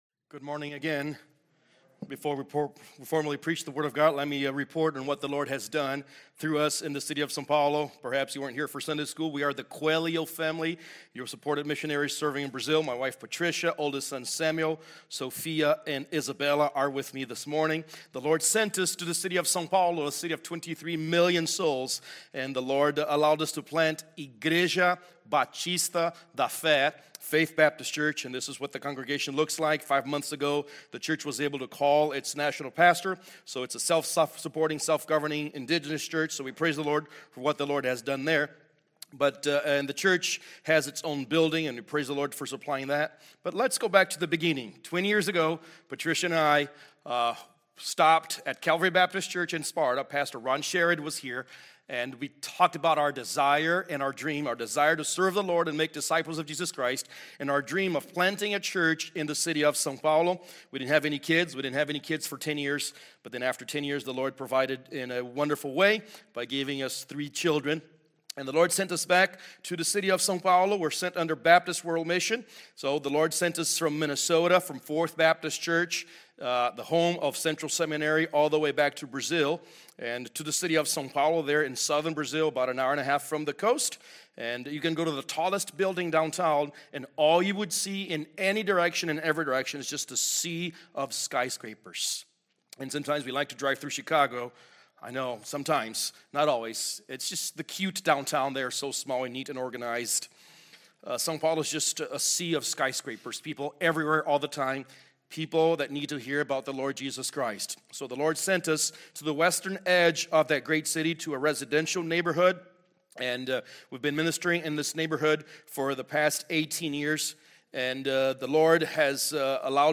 Passage: 1 Thessalonians 1 Service Type: Sunday Morning Service A message and update from our missionary to Brazil